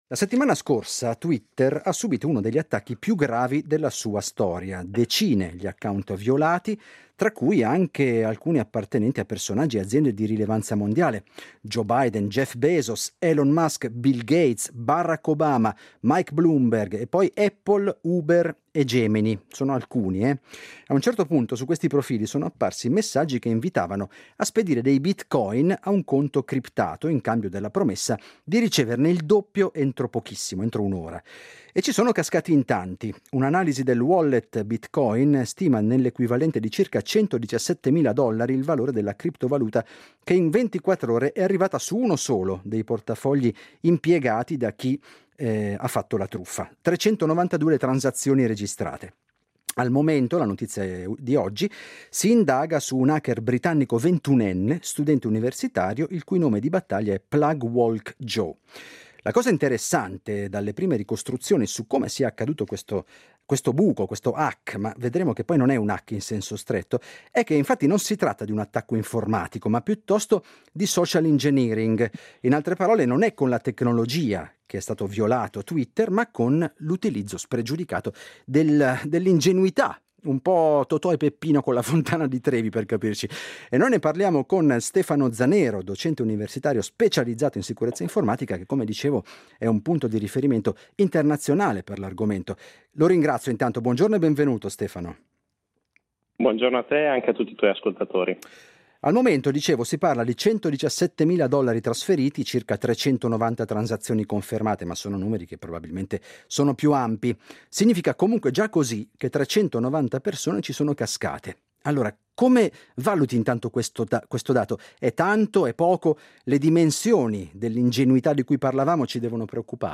Ne parliamo con l’esperto internazionale di sicurezza informatica